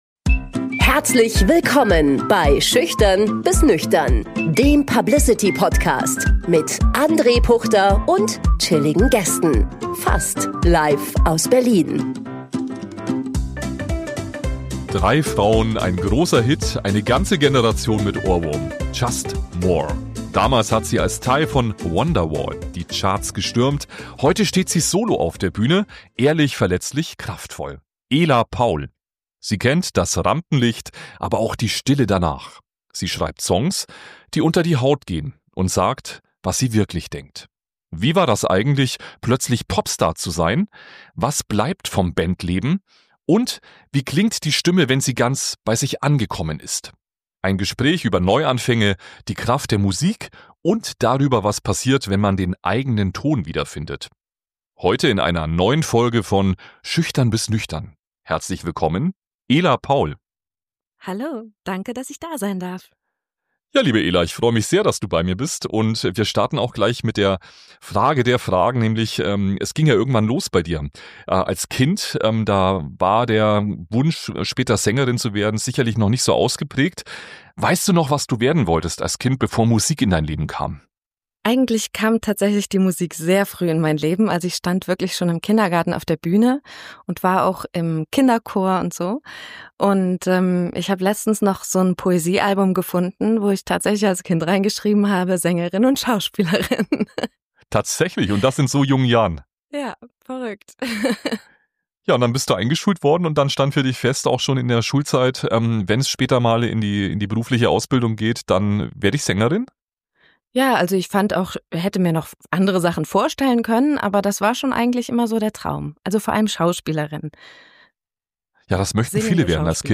Ein Gespräch über Neuanfänge, die Kraft der Musik – und darüber, was passiert, wenn man den eigenen Ton wiederfindet.